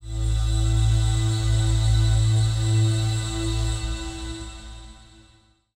SYNTHPAD028_PROGR_125_A_SC3.wav